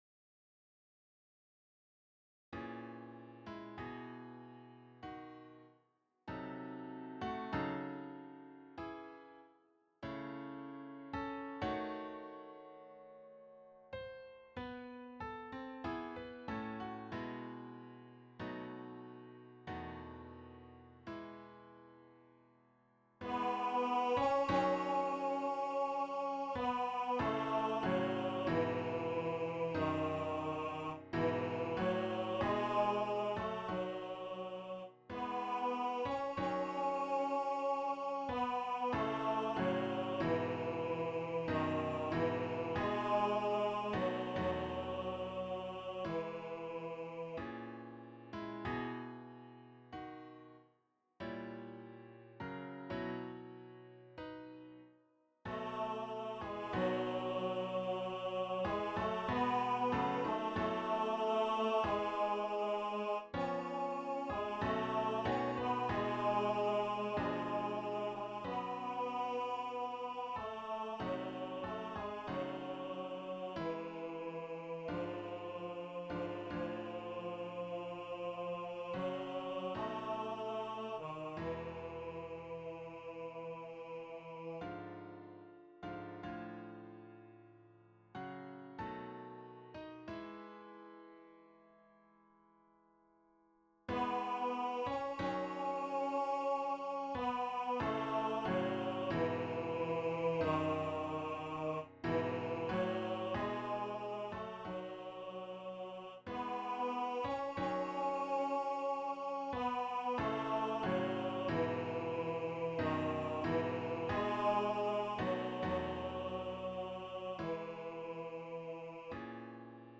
SINGEN: Lieder und Arien für Bass/Bariton